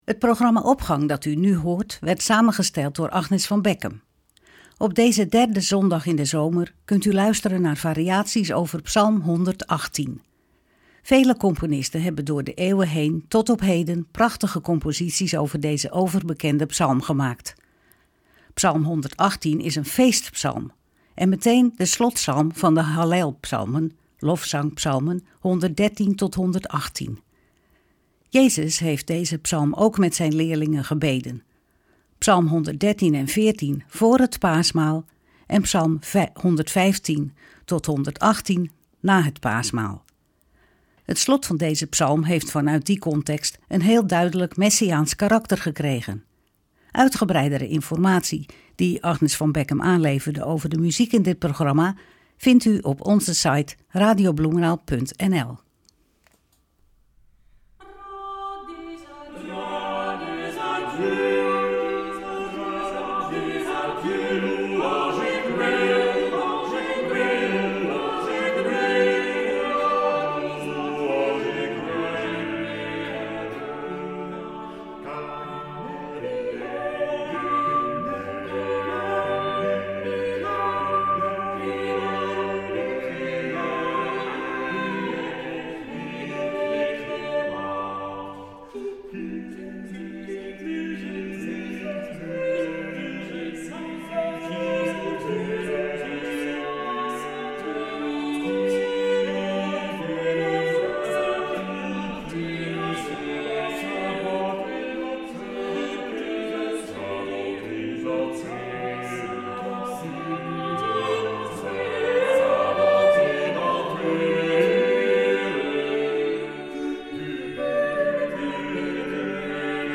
orgelimprovisatie
instrumentale variatie